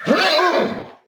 combat / creatures / horse / he / hurt3.ogg
hurt3.ogg